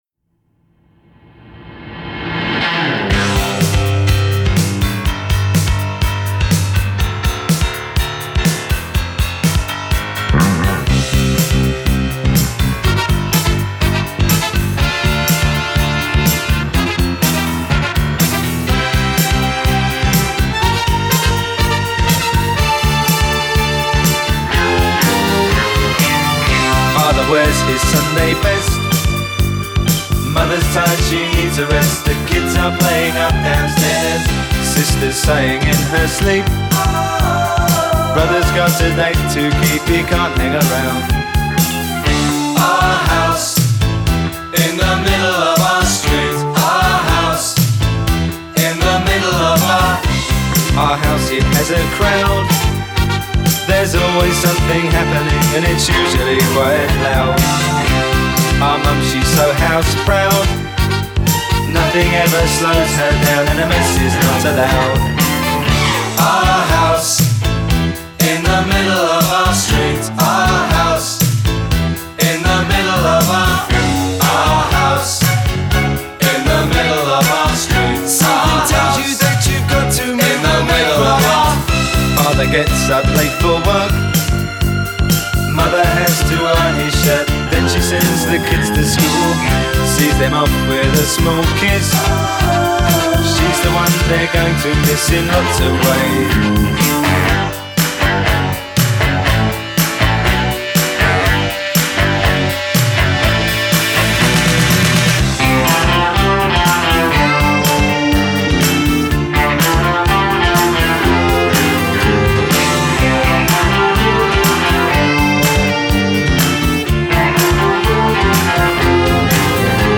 Genre: Ska, Two-Tone, New Wave, Reggae